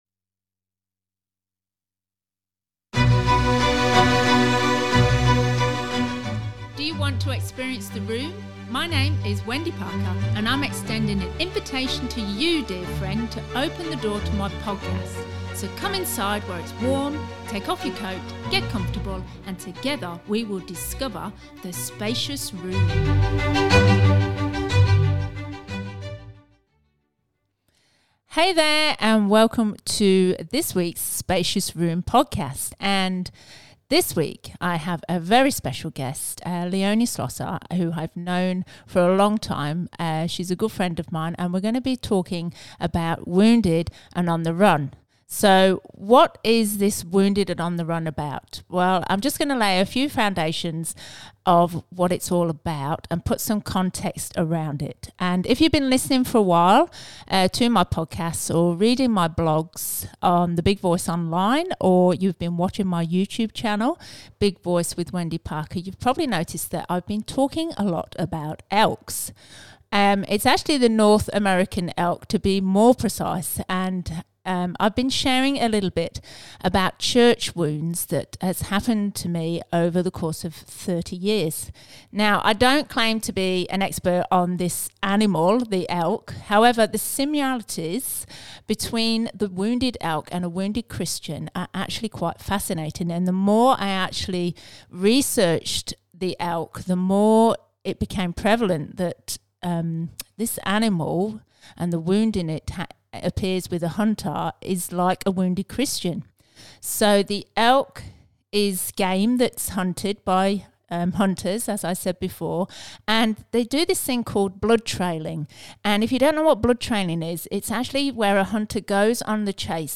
Wounded And On The Run: Interview
This healthy conversation around a subject that isn't always spoken about in church circles sheds light where there has been only darkness.